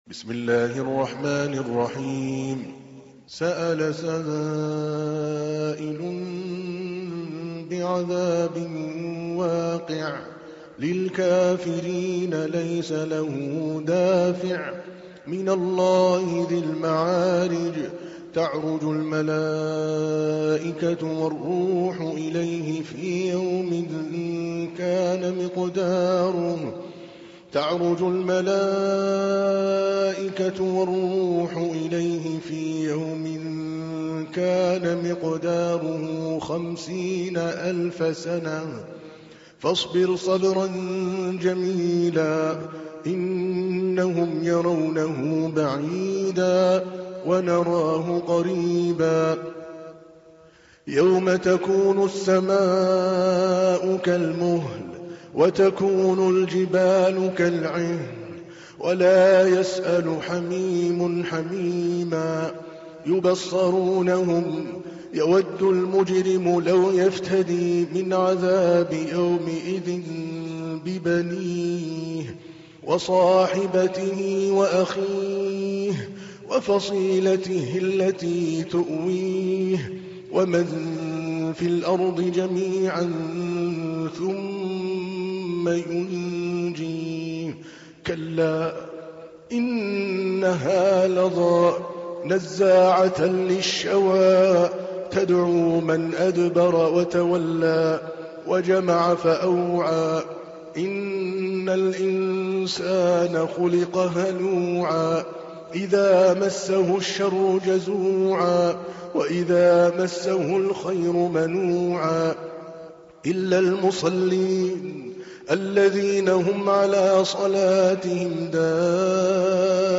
تحميل : 70. سورة المعارج / القارئ عادل الكلباني / القرآن الكريم / موقع يا حسين